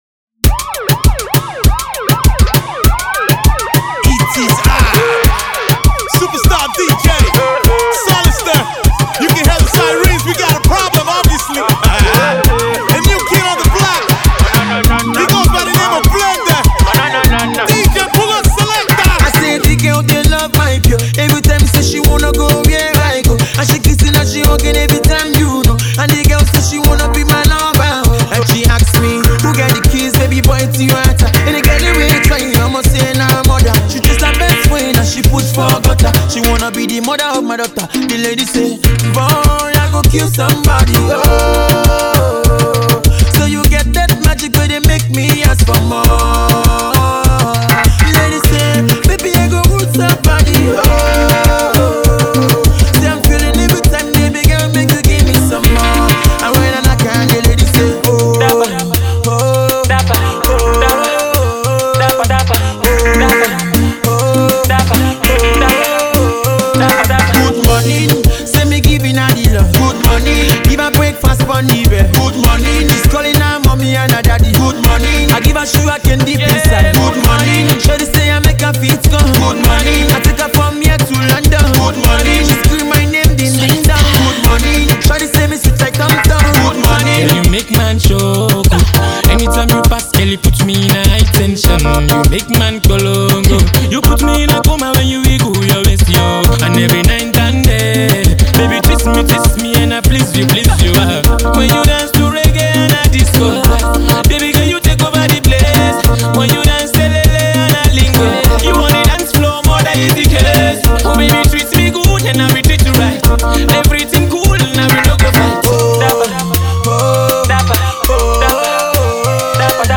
Decent Pop Tune